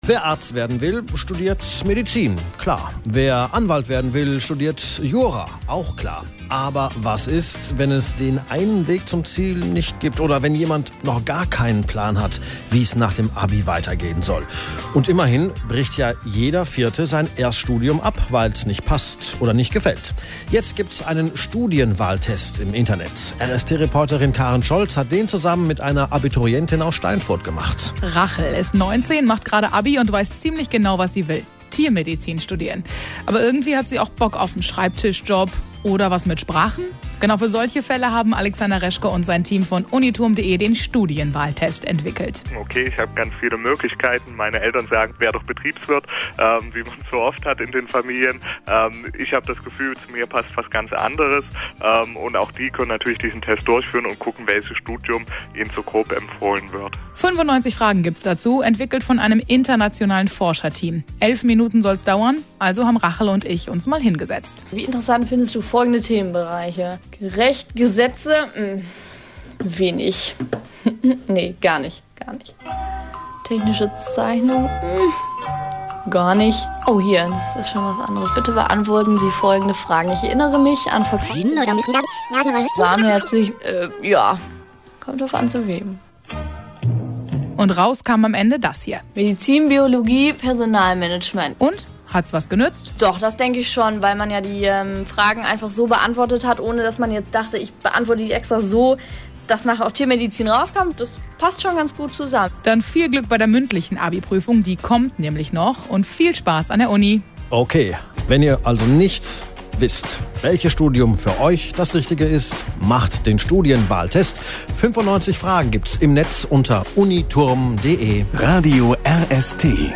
Radio-Interview zum Studienwahltest auf RADIO RST